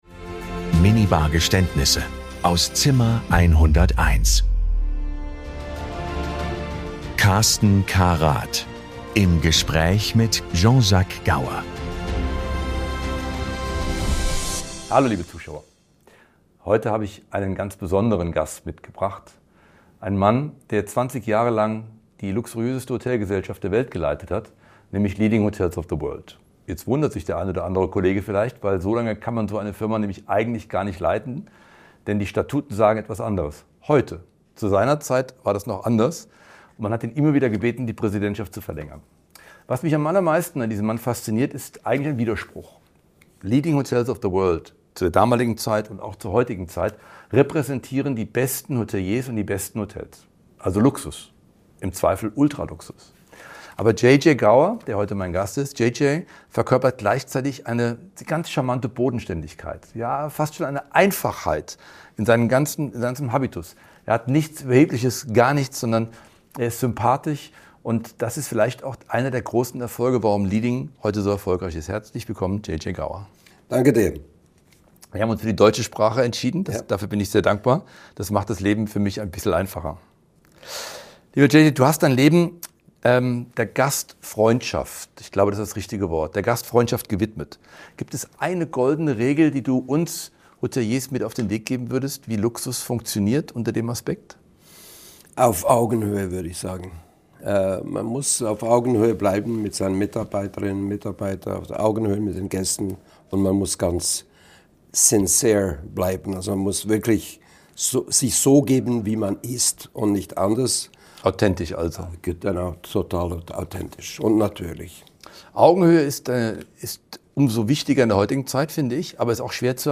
Es wird reflektiert, offen gesprochen und manchmal auch zurückgeblickt.
Ein Gespräch über Haltung, Verantwortung und Vermächtnis. Über Führung über Jahrzehnte hinweg und darüber, was wahre Exzellenz jenseits von Trends ausmacht.